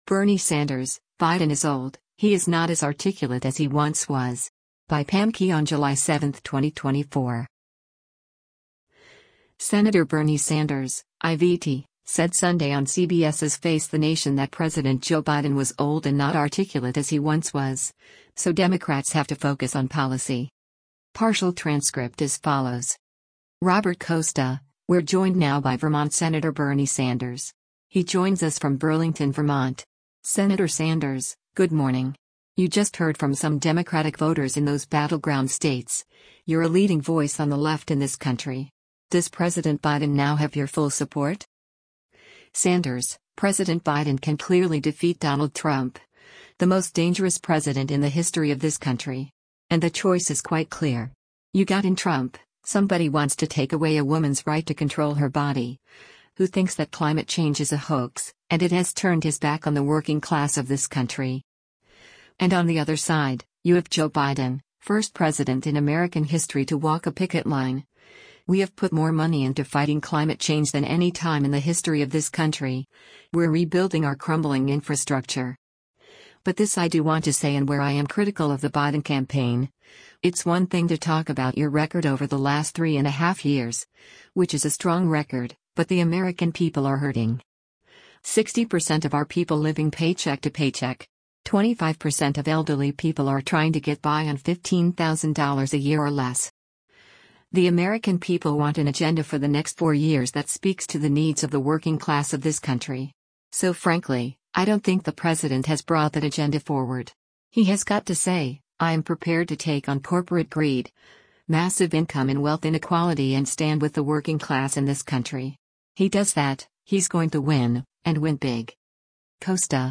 Senator Bernie Sanders (I-VT) said Sunday on CBS’s “Face the Nation” that President Joe Biden was “old” and “not articulate as he once was,” so Democrats have to focus on policy.